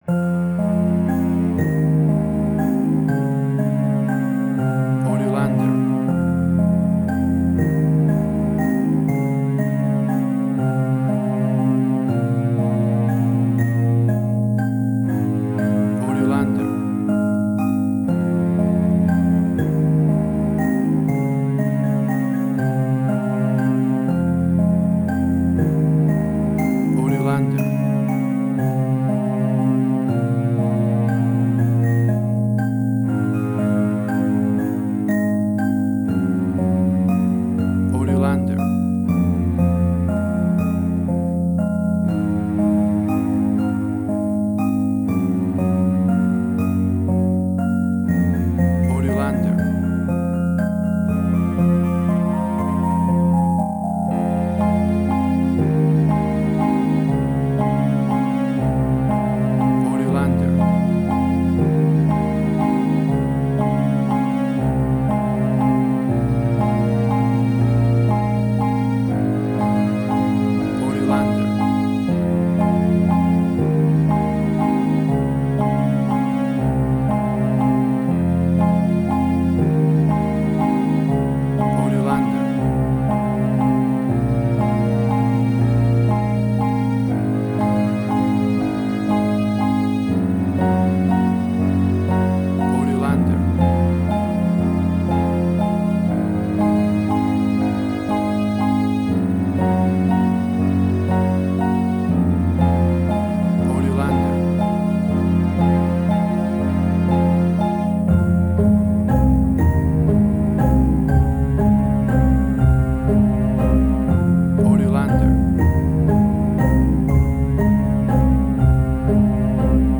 Ambient Strange&Weird.
Tempo (BPM): 120